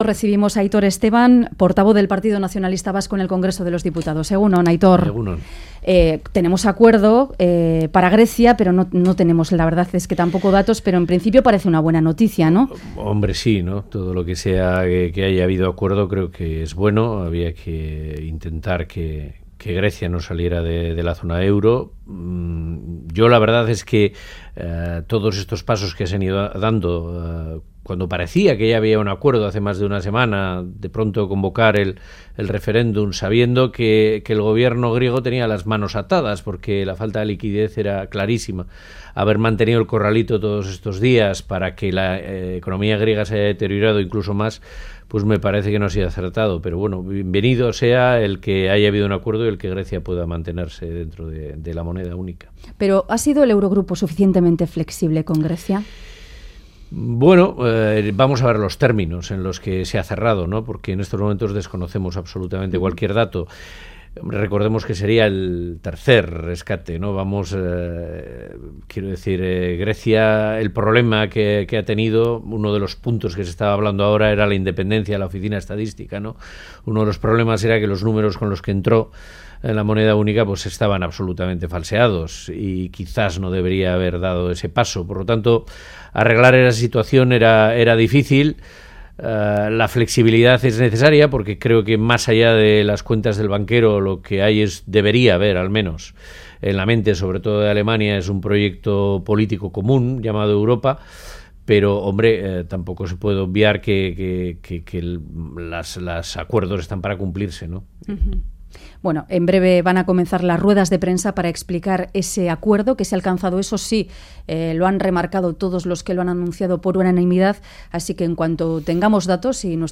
Radio Euskadi BOULEVARD El PNV no espera gran cosa de la reunión entre Urkullu y Pedro Sánchez Última actualización: 13/07/2015 09:35 (UTC+2) En entrevista al Boulevard de Radio Euskadi, el portavoz del PNV en el Congreso, Aitor Esteban, ha afirmado que no espera gran cosa de la reunión que esta semana mantendrán en Vitoria el Lehendakari y el líder del PSOE, Pedro Sánchez. Ha reiterado que para alcanzar un acuerdo los socialistas tendrán que reconocer a Euskadi como nación y una relación bilateral con el Estado, y ha acusado al PP de haber administrado con soberbia su mayoría absoluta. Sobre Cataluña, ha reconocido el enmarañamiento del proceso soberanista, y ha augurado que las próximas elecciones serán poco plebiscitarias.